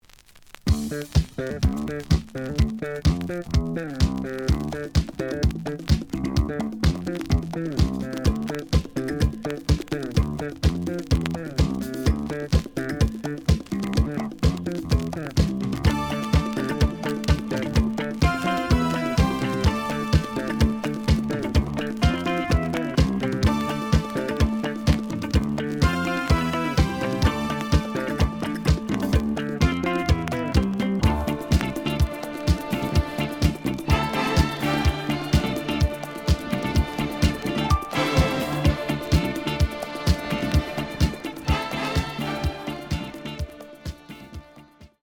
試聴は実際のレコードから録音しています。
●Format: 7 inch
●Genre: Disco